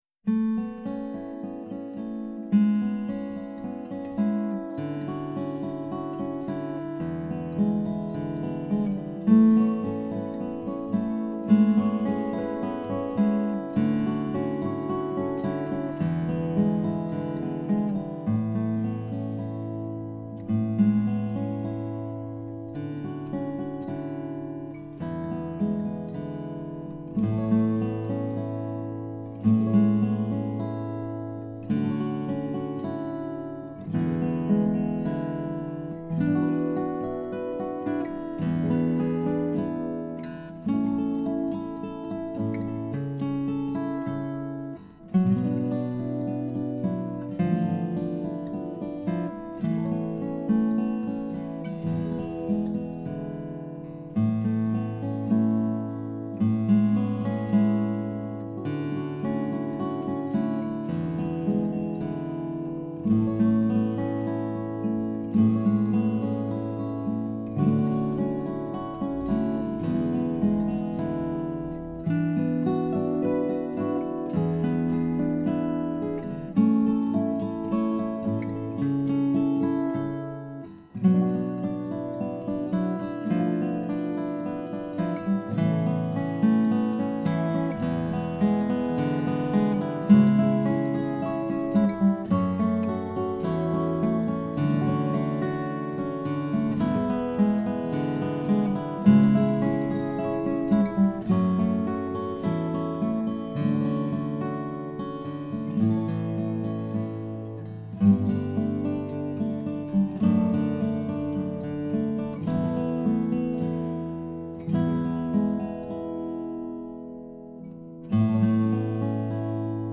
Below are some instrumental examples for this song from last year:
in-the-past-guitar-mix-9-15-19.mp3